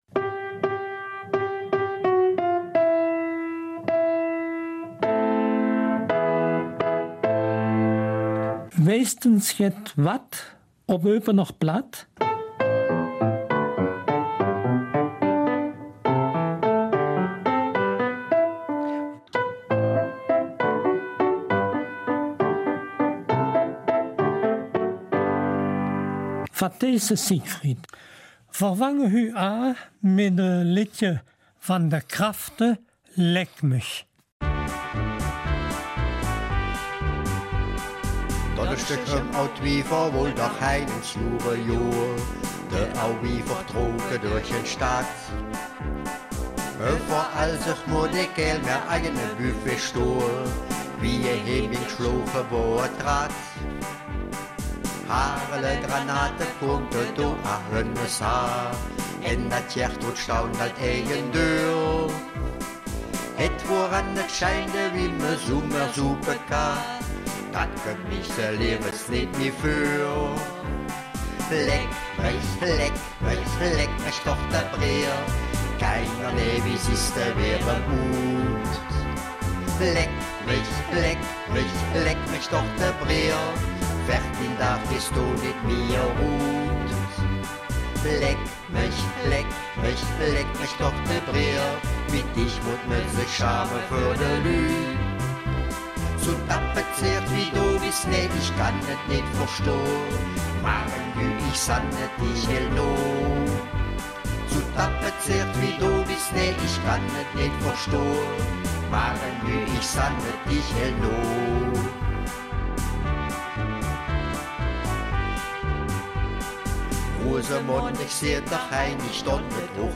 Eupener Mundart - 8. Oktober